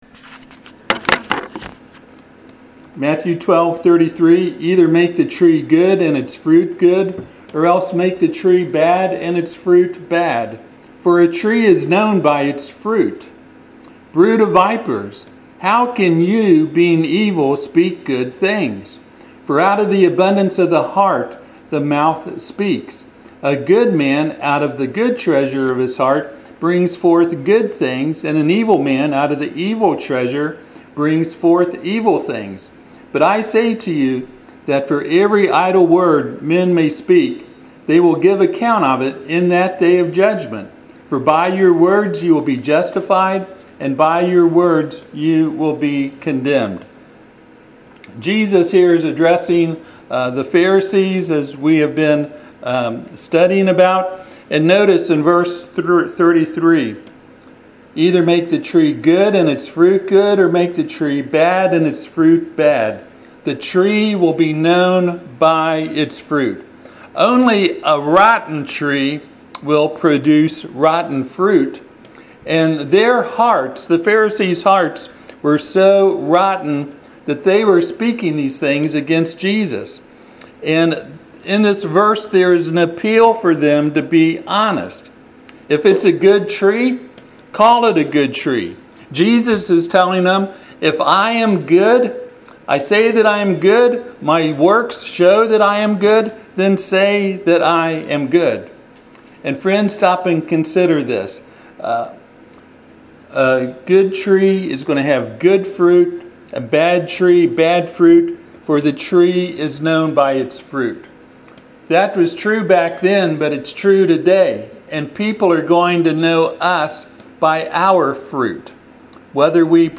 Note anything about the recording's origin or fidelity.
Passage: Matthew 12:35 Service Type: Sunday AM « I Will Allure Her How Do We Deal with The Desires God Has Given Us?